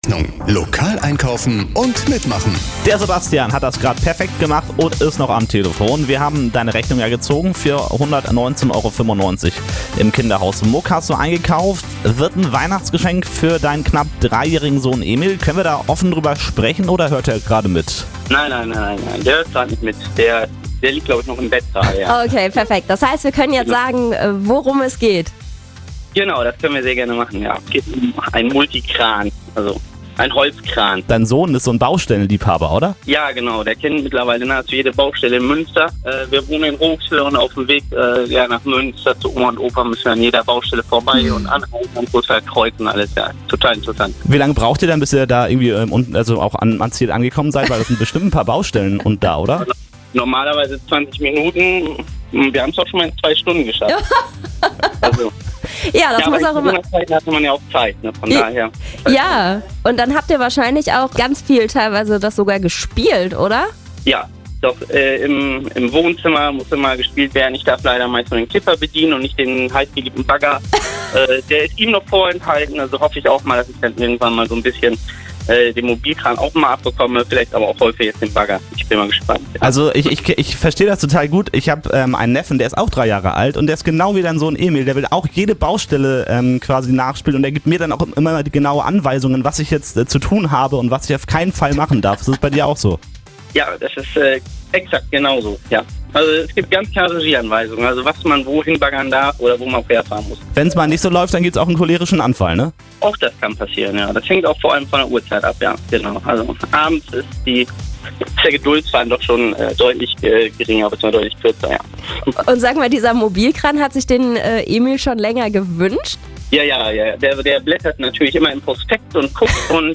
Hörer